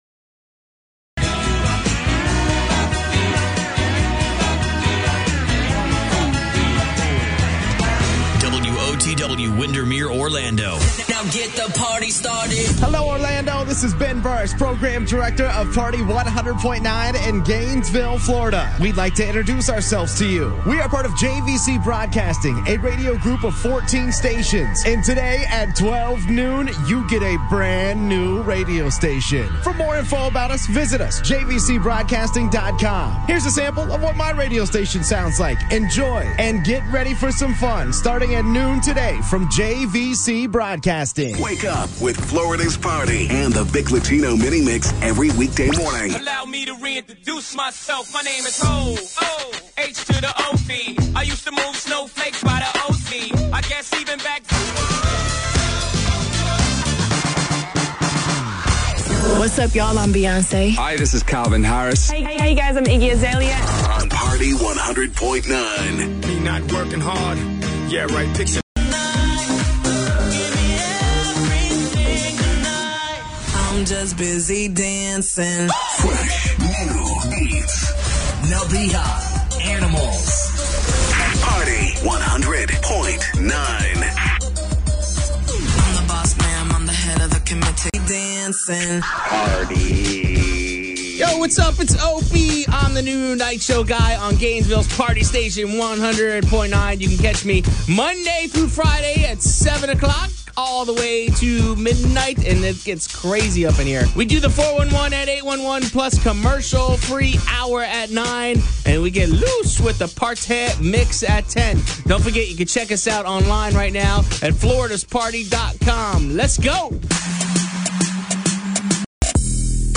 Previous Format: Spanish CHR “KQ 103” WHKQ
New Format: Country “103.1 The Wolf” WOTW